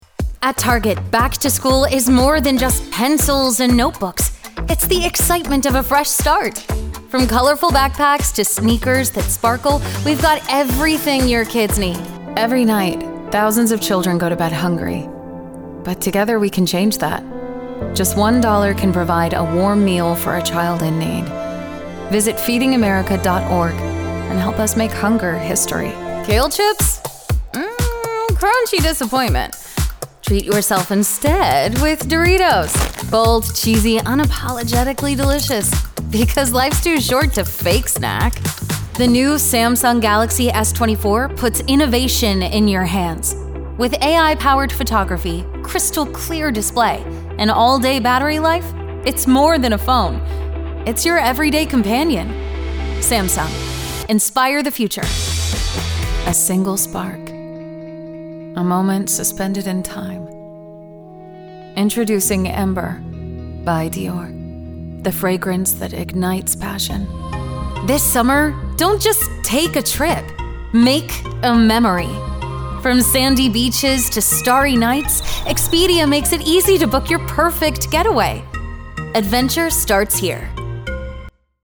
Classic Warmth, Modern Confidence
Commercial Demo Reel